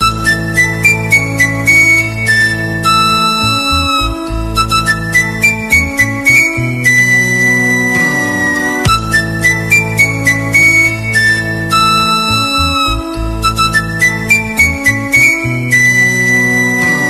Category: Flute Ringtones